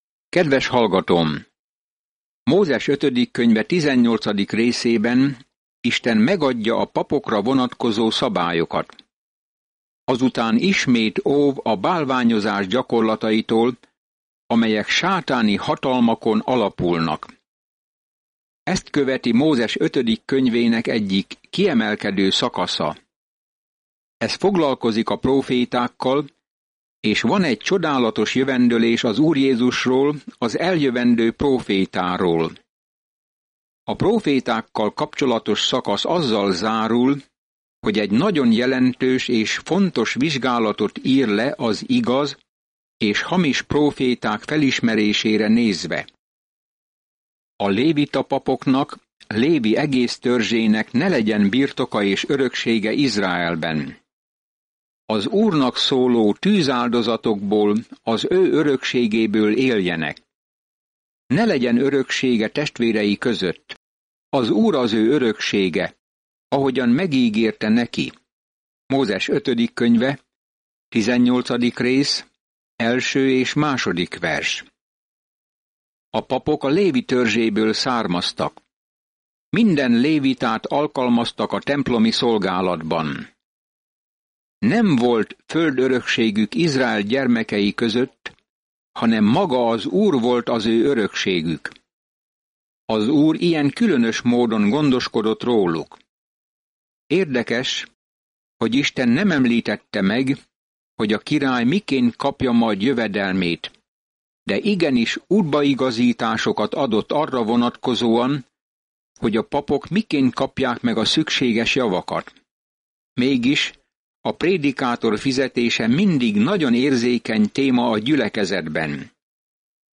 Szentírás 5Mózes 18 Nap 13 Olvasóterv elkezdése Nap 15 A tervről A Deuteronomium összefoglalja Isten jó törvényét, és azt tanítja, hogy az engedelmesség a mi válaszunk az Ő szeretetére. Napi utazás a Deuteronomiumban, miközben hallgatod a hangos tanulmányt, és olvasol kiválasztott verseket Isten szavából.